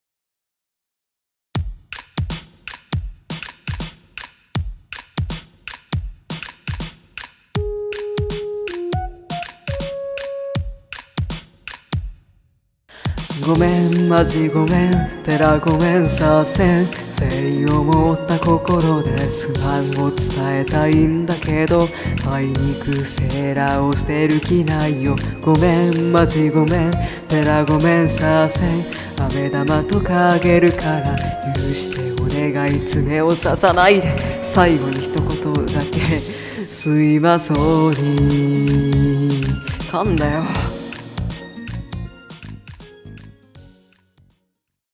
♪---オク下で気持ち悪い、あと噛んだ